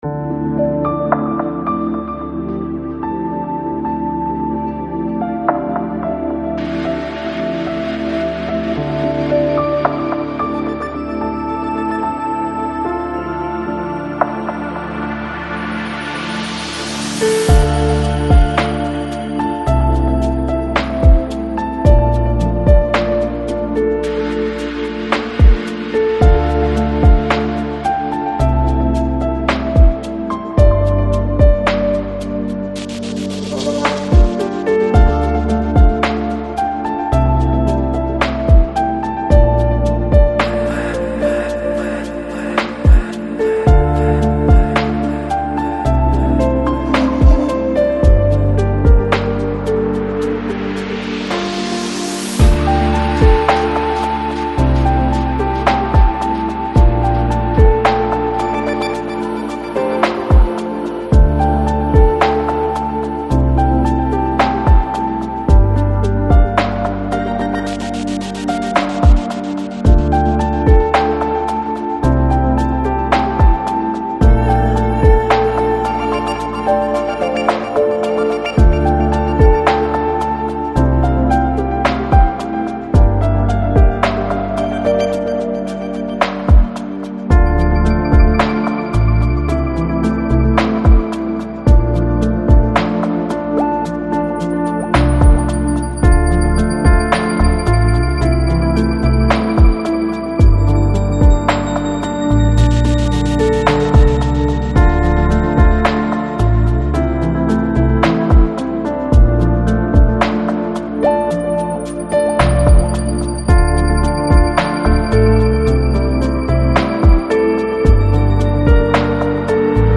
Жанр: Downtempo, Lounge, Chill Out, Lo Fi